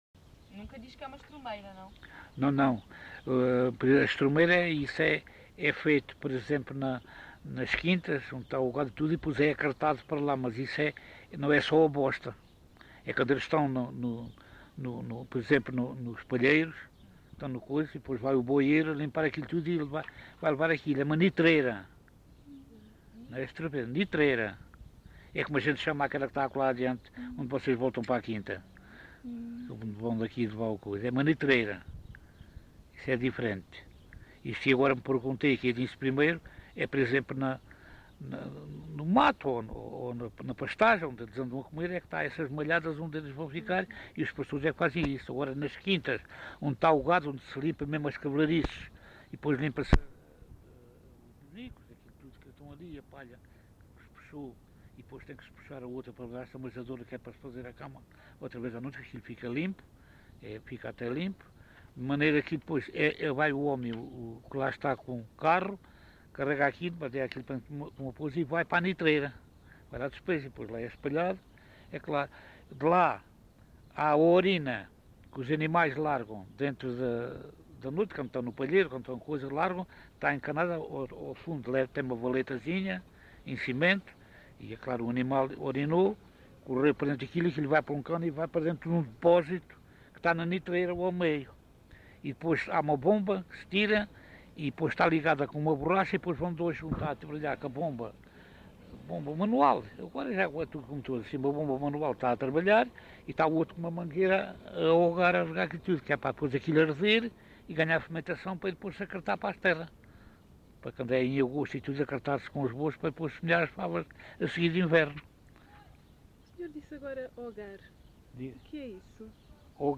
LocalidadeMontalvo (Constância, Santarém)